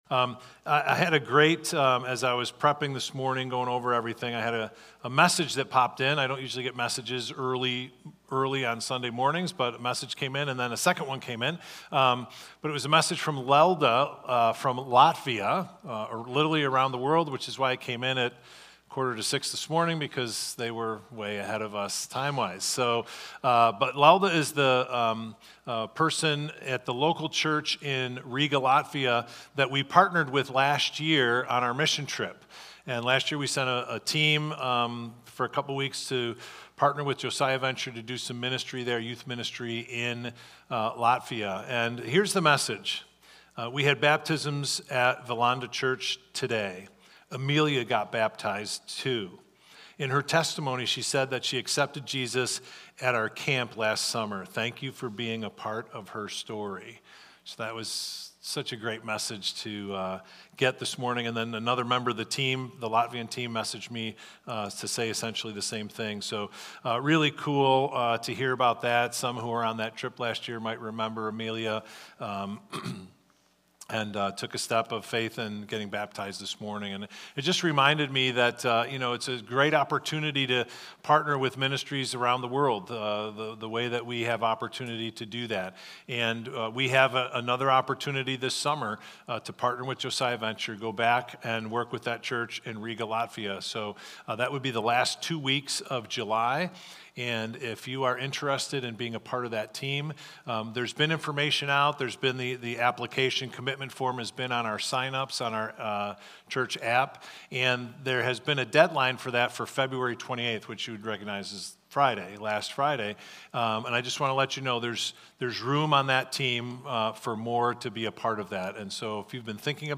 Victor Community Church Sunday Messages / Q&A - Why Do I Feel Distant From God?